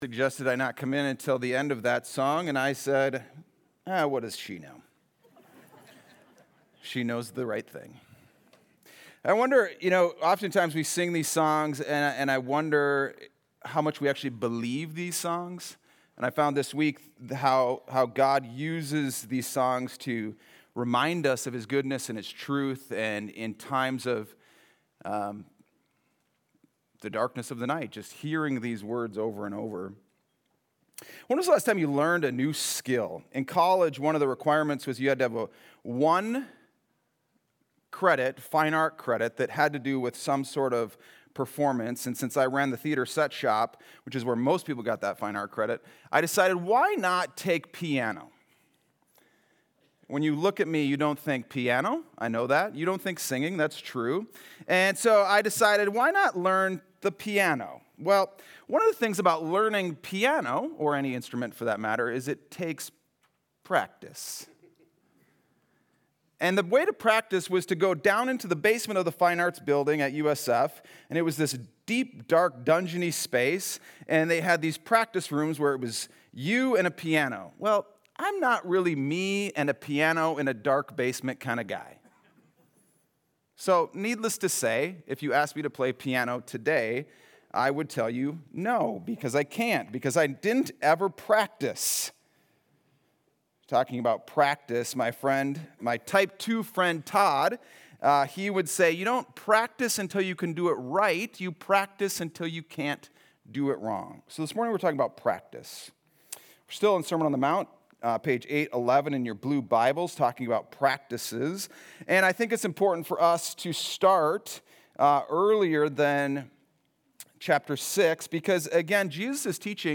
Sunday Sermon: 8-24-25